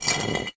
brick-move.ogg